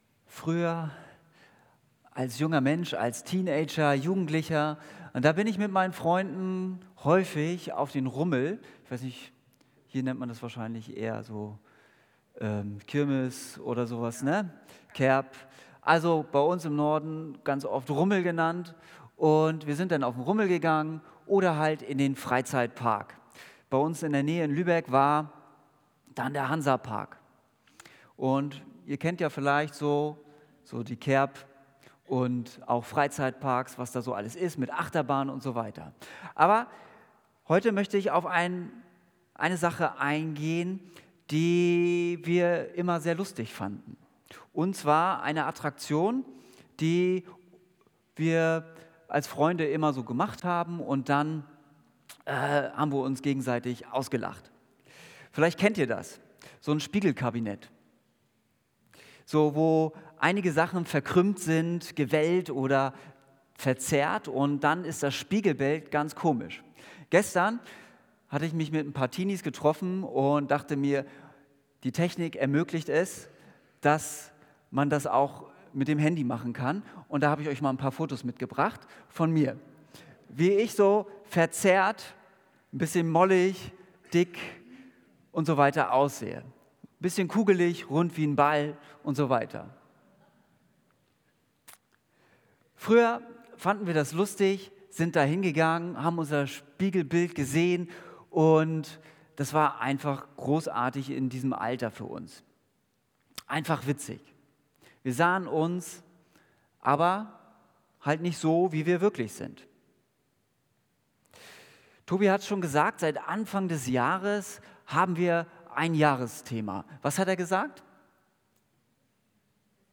Predigt Im Spiegel Jesu